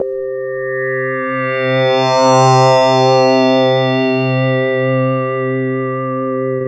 SI2 SWELL0AR.wav